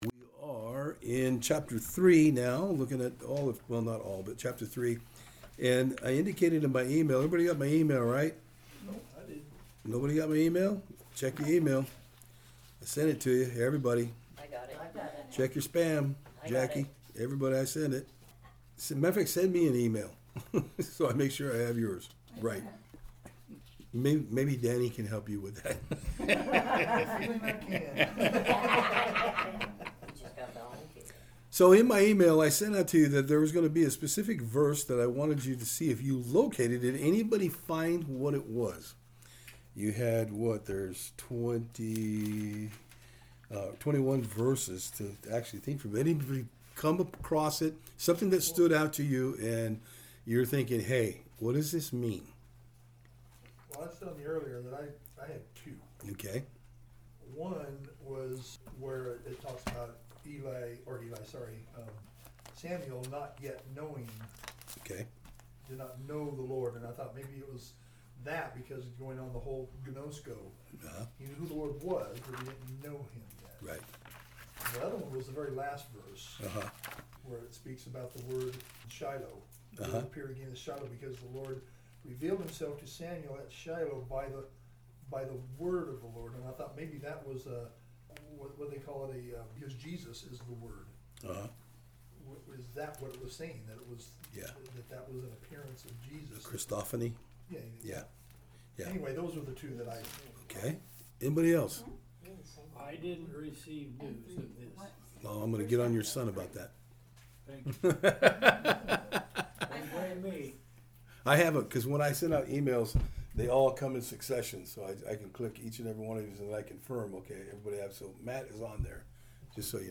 1 Samuel 3:1-10 Service Type: Thursday Afternoon In our study today we will be looking at how God spoke to young Samuel.